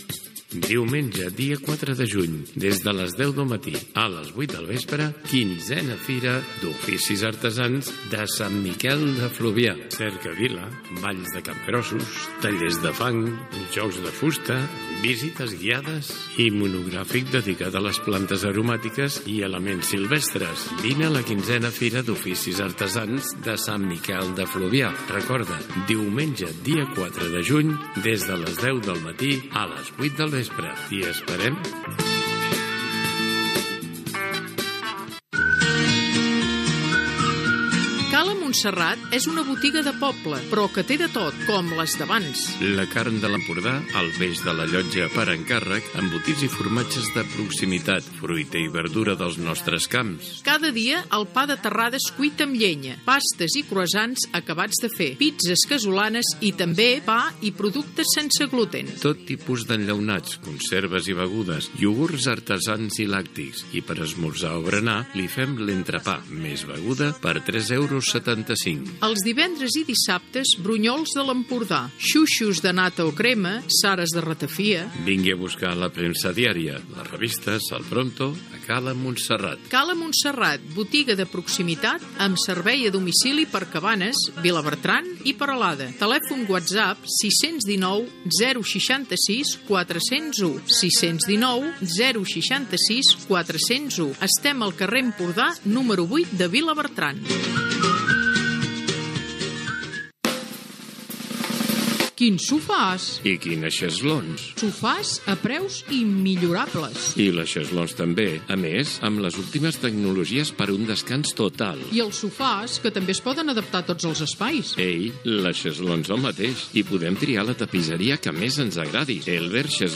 Publicitat, indicatiu cantat de l'emissora i tema musical.
FM